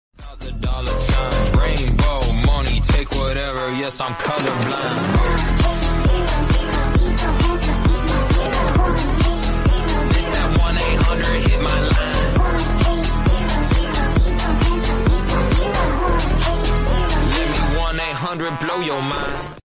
The absolute beauty of storm sound effects free download